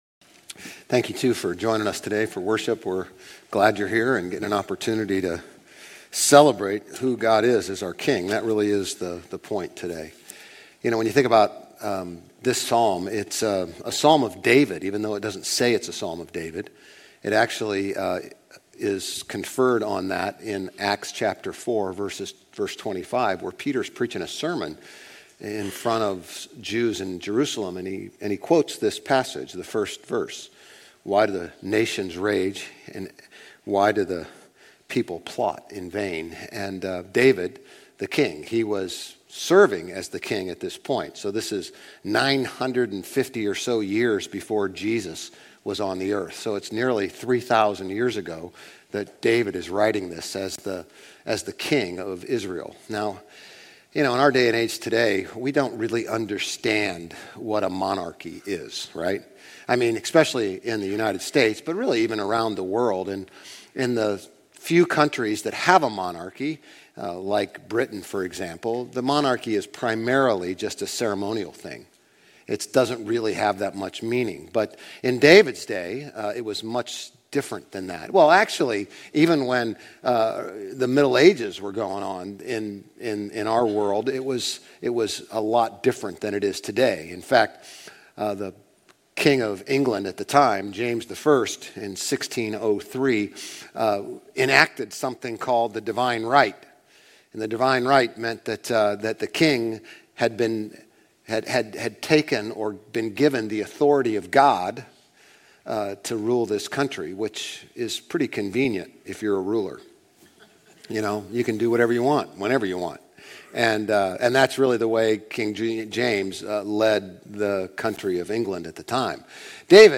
Grace Community Church Old Jacksonville Campus Sermons Psalm 2 - Kingship Jul 21 2024 | 00:34:21 1x 00:00 / 00:34:21 Subscribe Share RSS Feed Share Link Embed